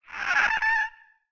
Minecraft Version Minecraft Version snapshot Latest Release | Latest Snapshot snapshot / assets / minecraft / sounds / mob / ghastling / ghastling2.ogg Compare With Compare With Latest Release | Latest Snapshot
ghastling2.ogg